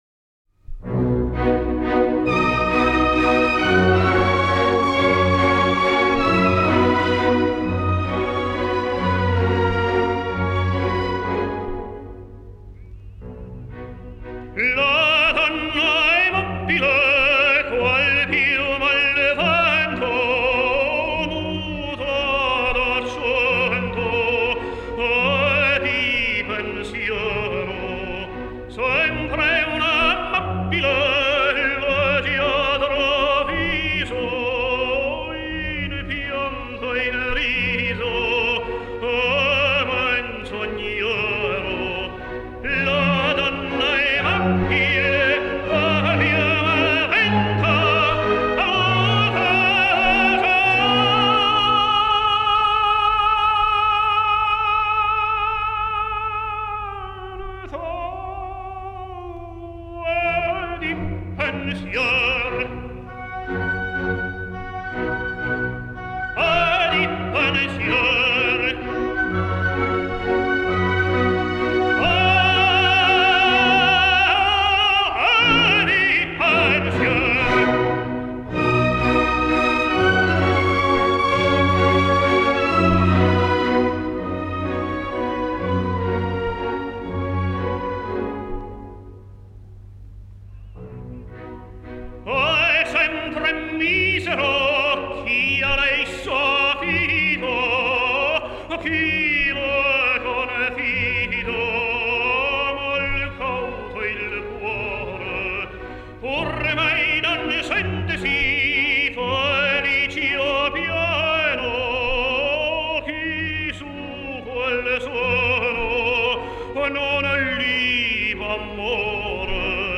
Великолепный тенор!